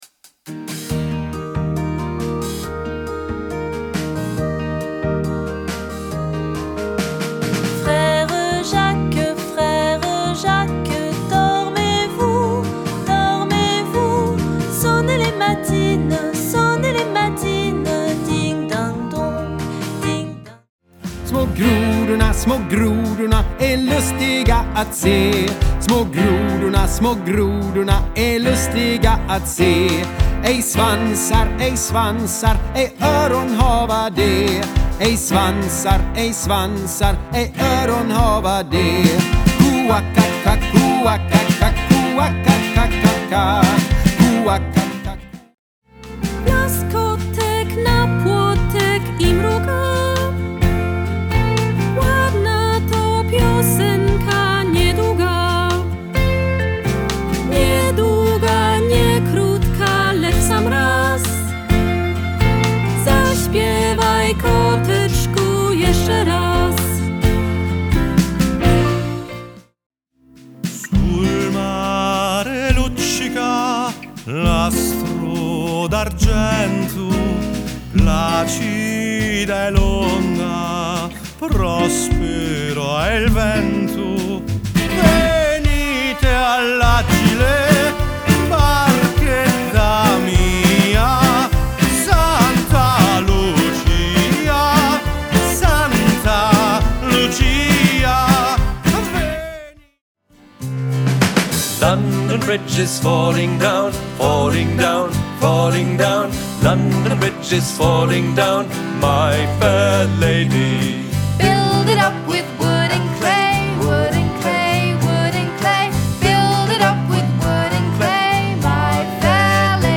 Eine musikalische Reise durch Europa Ob griechische Folklore
Musik